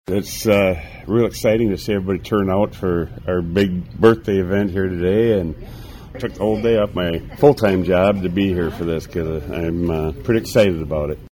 Prairie du Chien threw a big party on Thursday to celebrate the community’s 150th birthday. The Community Room at City Hall had a steady stream of people stopping by for cake and coffee and other treats, and reminiscing:
That’s Prairie du Chien Mayor Dave Hemmer.